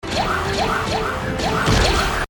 1. Padme's Cool Blaster Noise
But no, it's the fact that they make such a bizarre, unique sound that I like so much.
It's just a fun alternative to the "pyew pyew pyew!" we're used to.
padme-blaster.mp3